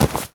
foley_object_grab_pickup_rough_01.wav